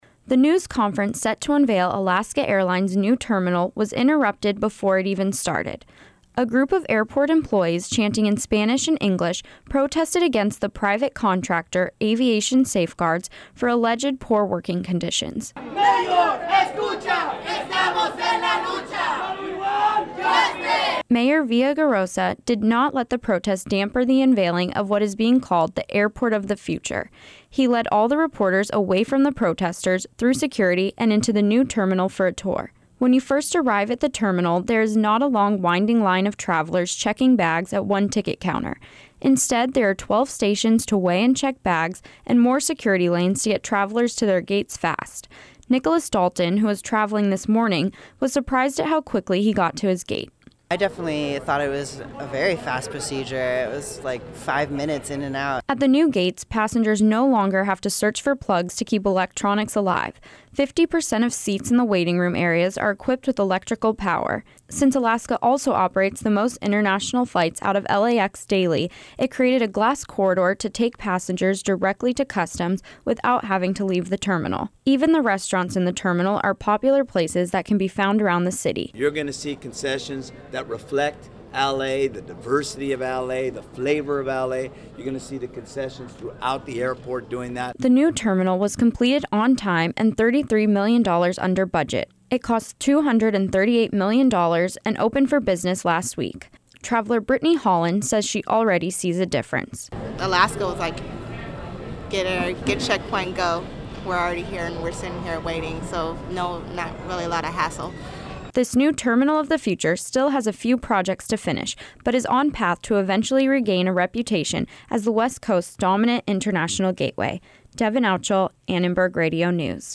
The news conference set to unveil Alaska Airlines new terminal was interrupted before it even started.
A group of airport employees chanting in Spanish and English protested against the private contractor Aviation Safeguards, for alleged poor working conditions.